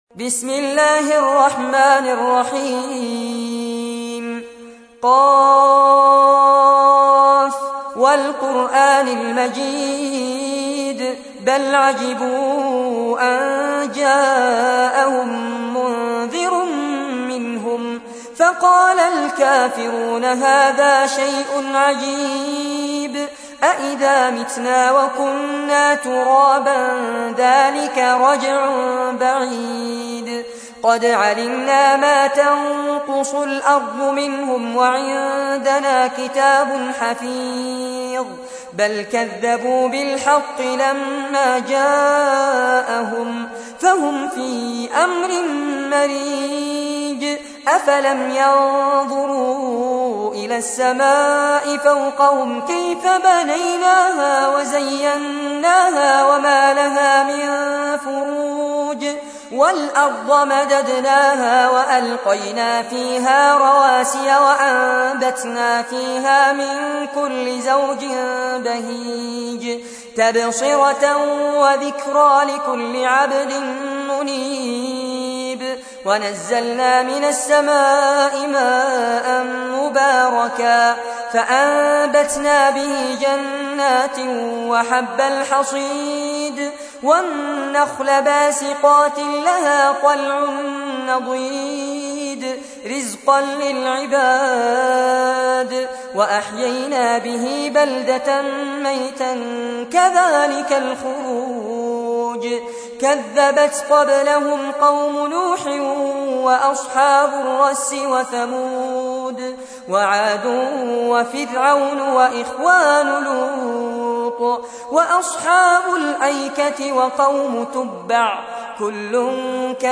تحميل : 50. سورة ق / القارئ فارس عباد / القرآن الكريم / موقع يا حسين